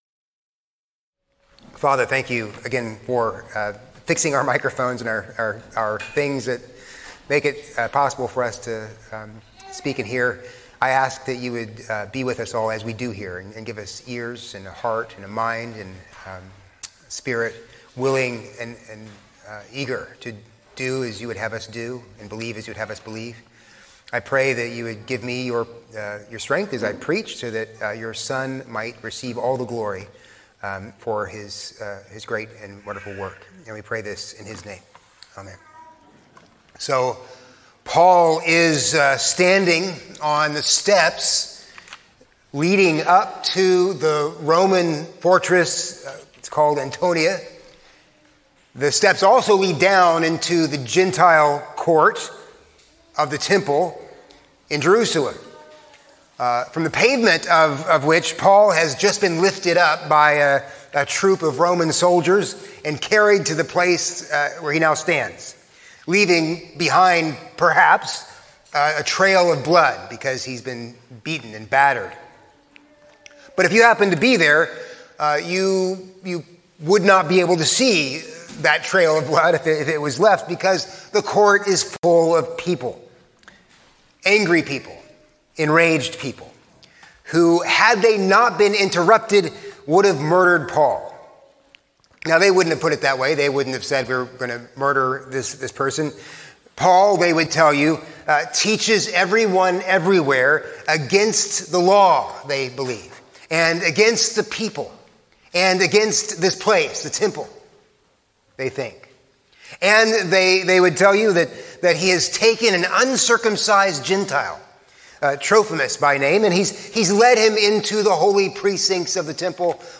A sermon on Acts 22:1-11